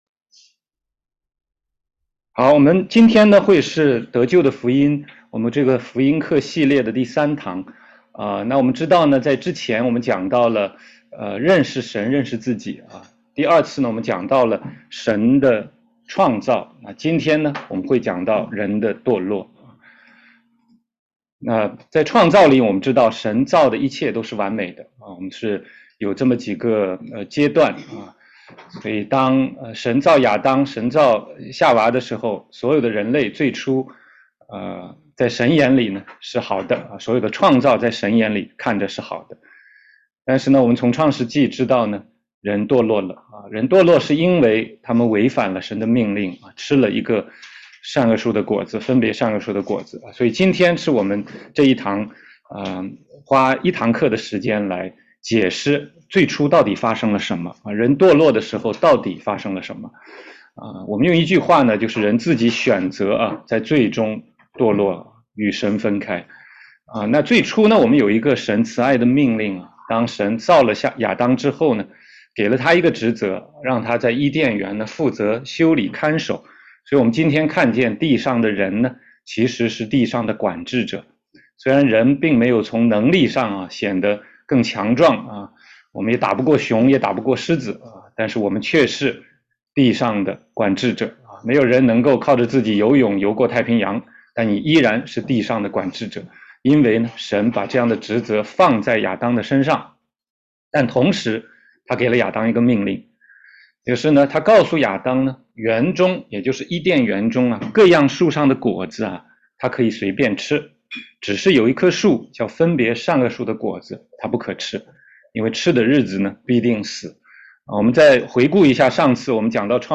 16街讲道录音 - 得救的福音第三讲：人堕落